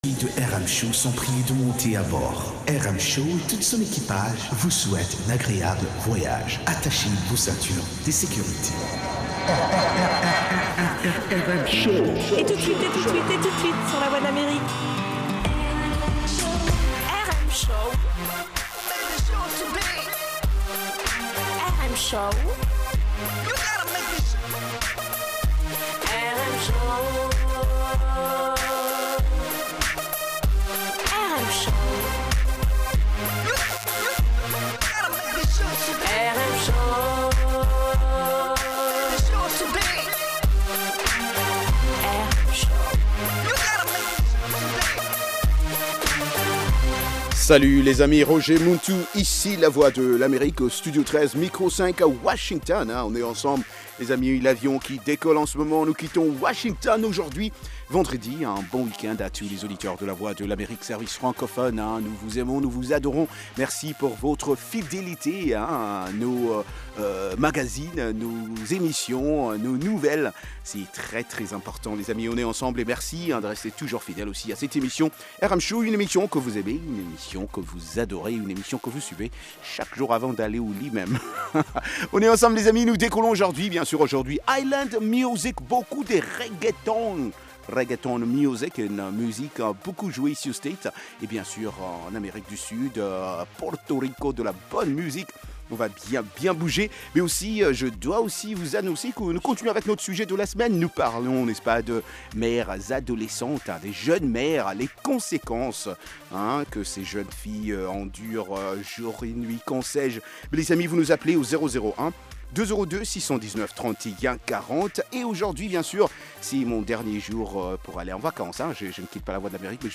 Zouk, Reggae, Latino, Soca, Compas et Afro, et interviews de&nbsp